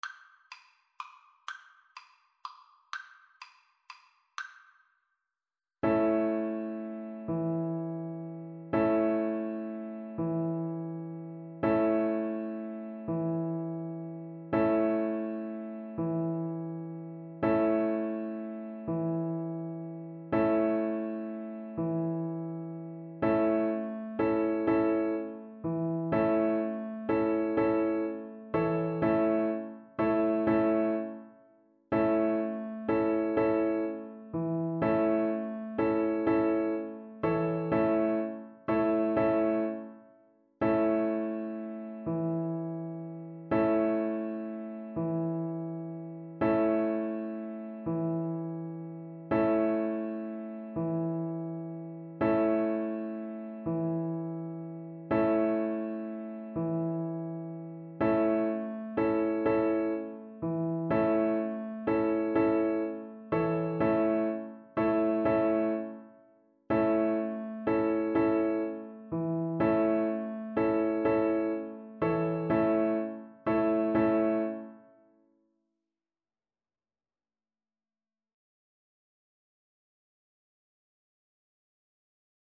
Traditional Trad. Sorida Violin version
Play (or use space bar on your keyboard) Pause Music Playalong - Piano Accompaniment Playalong Band Accompaniment not yet available transpose reset tempo print settings full screen
Violin
3/4 (View more 3/4 Music)
A major (Sounding Pitch) (View more A major Music for Violin )
Slow one in a bar .= c.60
Traditional (View more Traditional Violin Music)